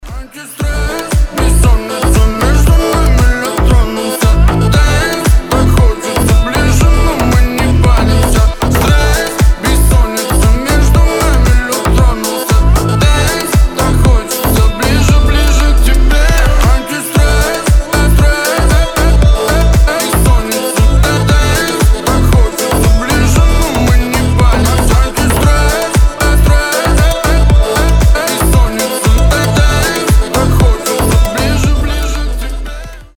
• Качество: 320, Stereo
громкие
басы
качающие